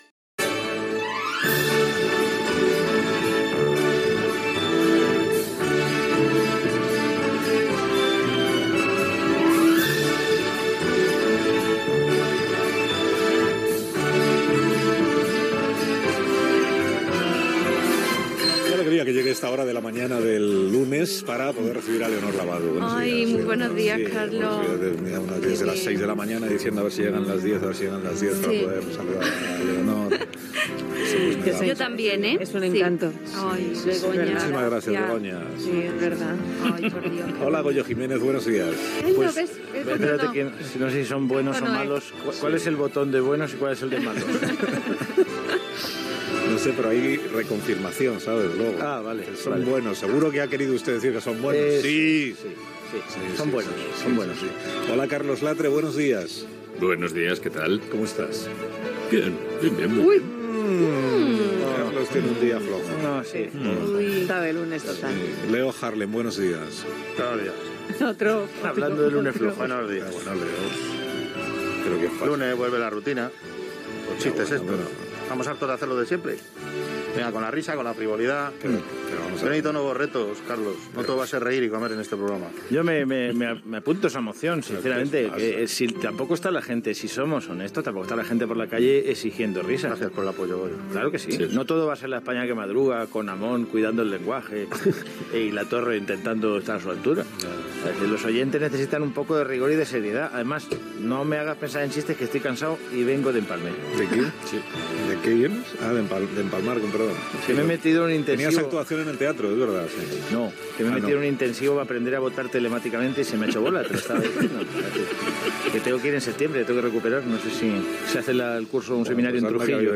"La hora guasa", diàleg entre els humoristes i entrevista a l'alcalde de Madrid José Luis Martínez-Almeida Gènere radiofònic Info-entreteniment